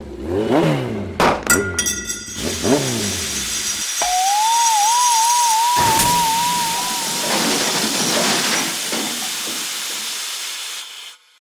Car breaks down
broken car cartoon engine sound effect free sound royalty free Movies & TV